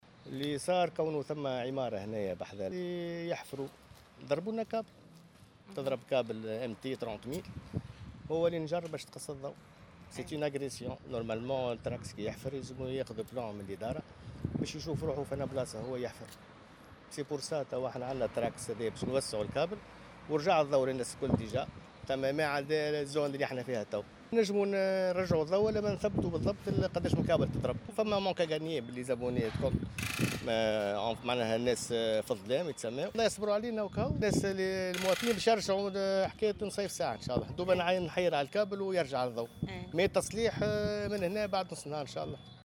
عون من الشركة التونسية للكهرباء والغاز تحميل المشاركة علي